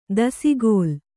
♪ dasigōl